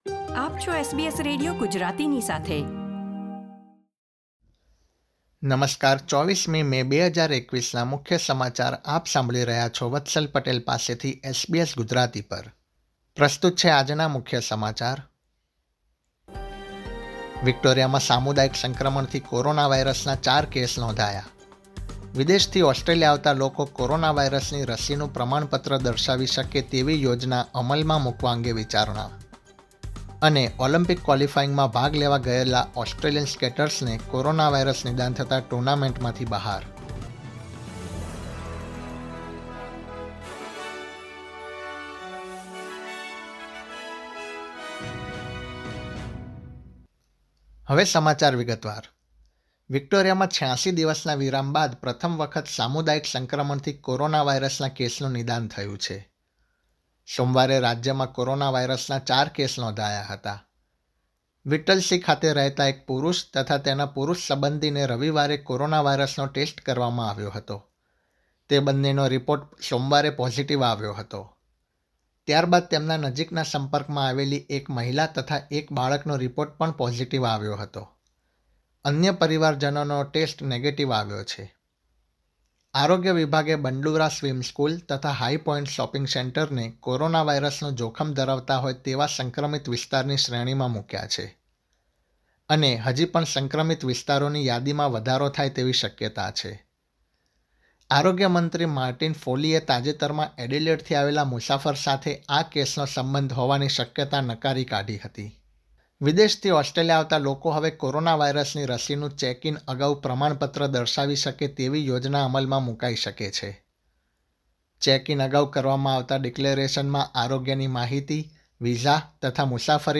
SBS Gujarati News Bulletin 24 May 2021
gujarati_2405_newsbulletin.mp3